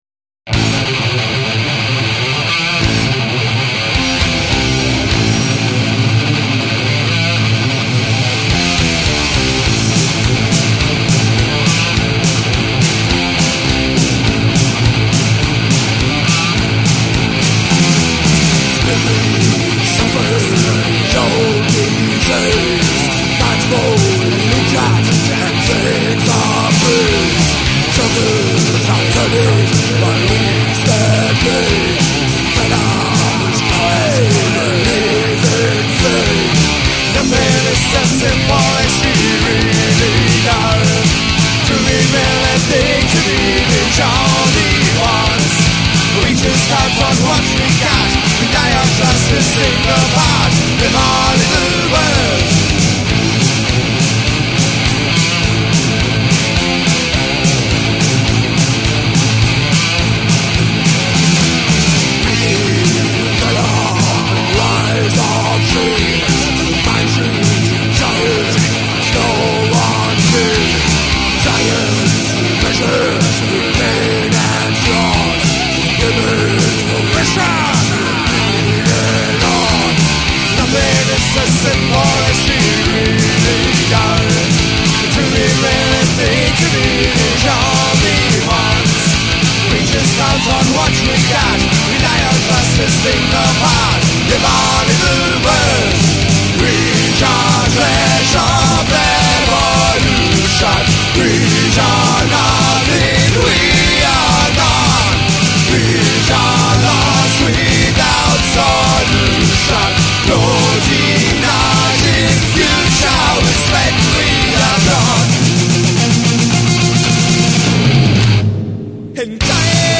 im Gerna Studio, Bochum (Deutschland)
Gesang
Gitarre, Keyboards
Bass
Schlagzeug